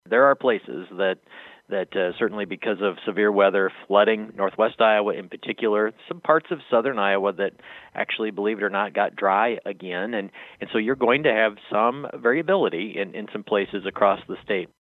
NAIG SAYS THERE’S NEVER 100 PERCENT PERFECT CONDITIONS ACROSS THE STATE, AND THAT’S EVIDENT AGAIN THIS YEAR.